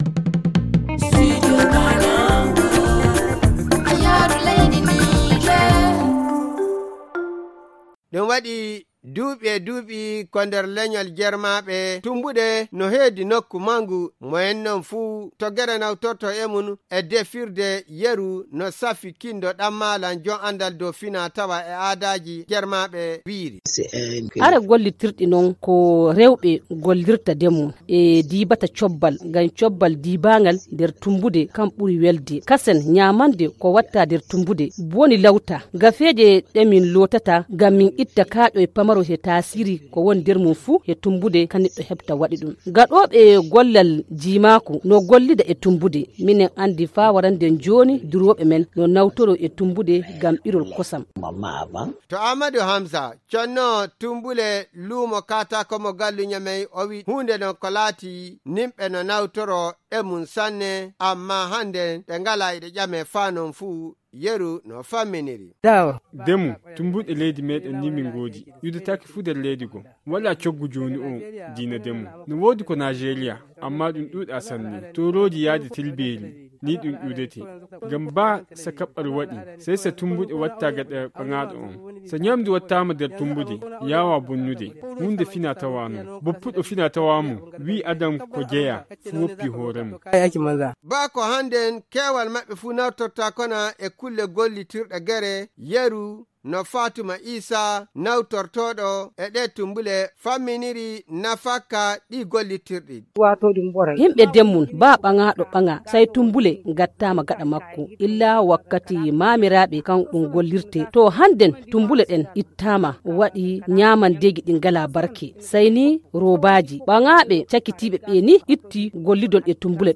Le magazine en fulfuldé